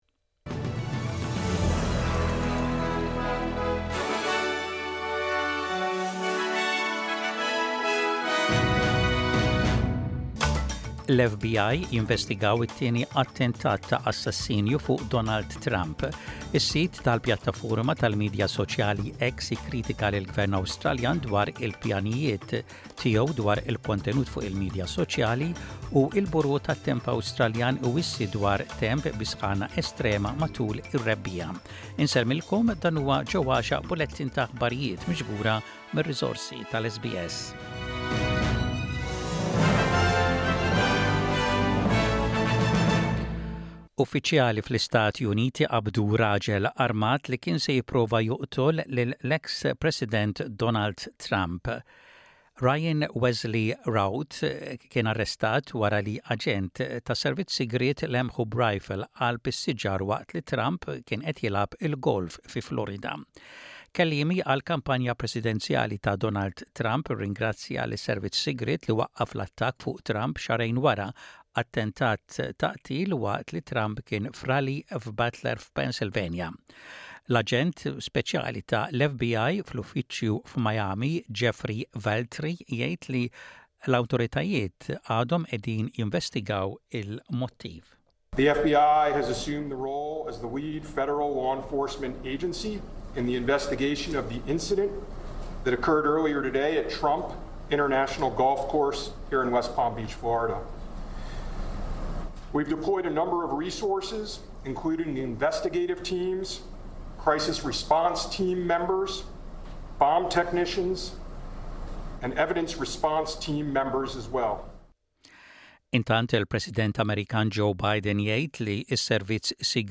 SBS Radio | Aħbarijiet bil-Malti: 17.09.24